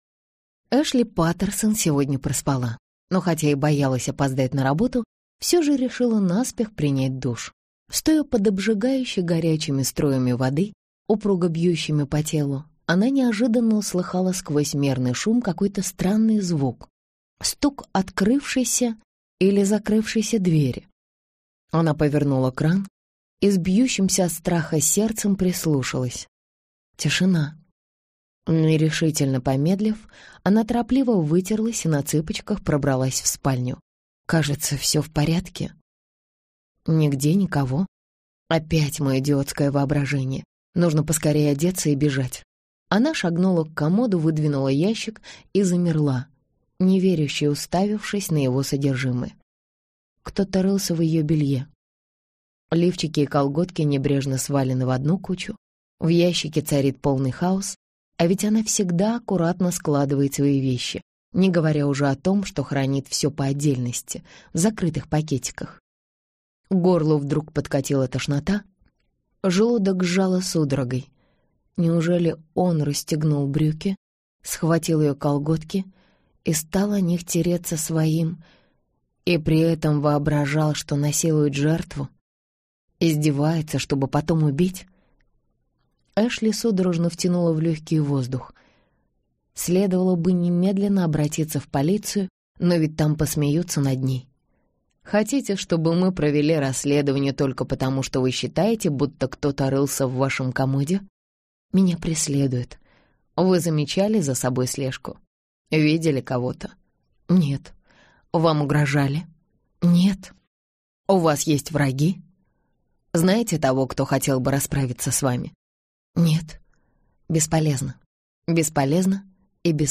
Аудиокнига Расколотые сны - купить, скачать и слушать онлайн | КнигоПоиск